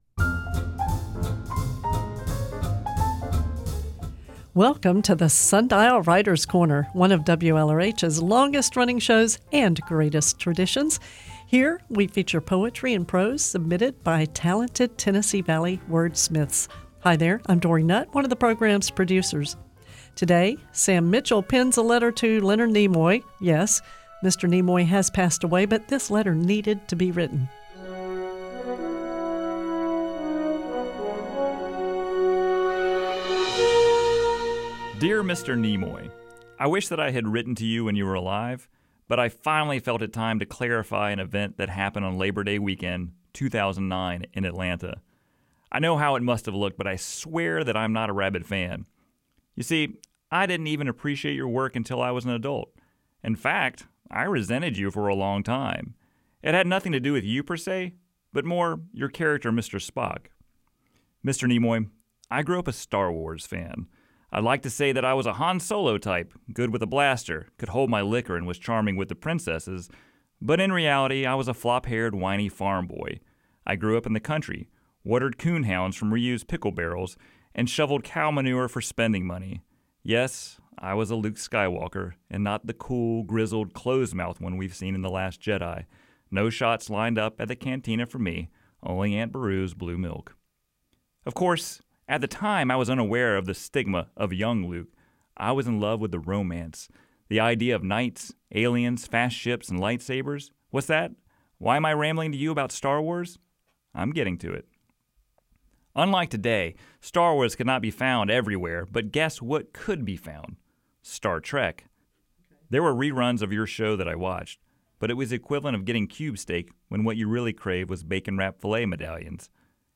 The following story was performed live for Flying Monkey Arts Theatre on August 26, 2017.